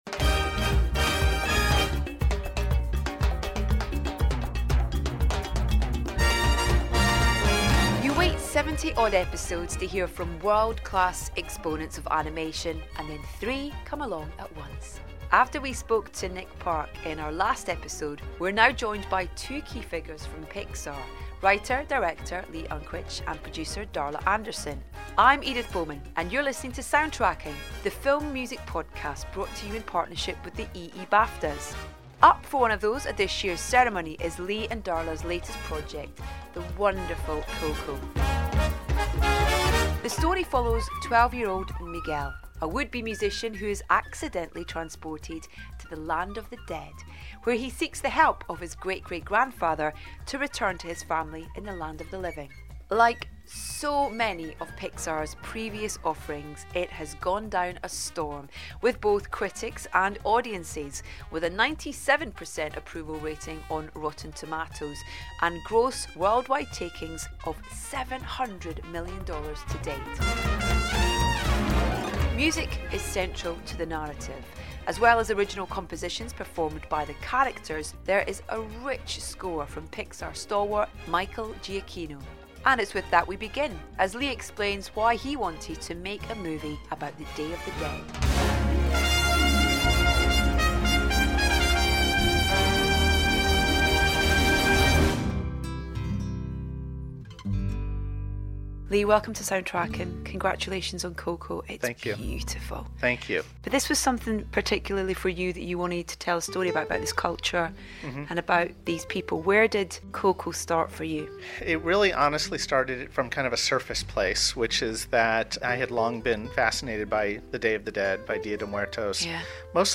Having just spoken to Nick Park, we're delighted to be joined by two key figures from the studios - writer / director Lee Unkrich and producer Darla Anderson. Having previously joined forces for Toy Story 3, Lee and Darla's latest project is the wonderful Coco.